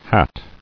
[hat]